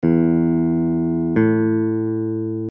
In the diagrams below, we are jumping from a note on an open string (any string will do) to another note on the same string.
Perfect Fourth = 2 ½  steps
perfect-4th.mp3